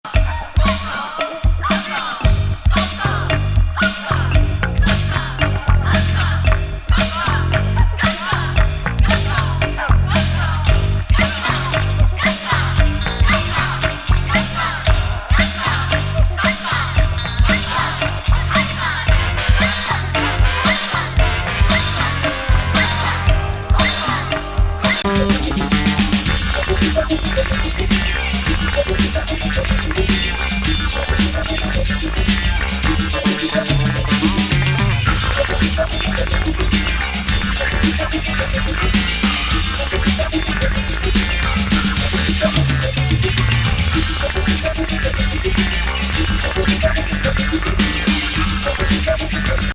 強力ヘビー・コールド・ファンク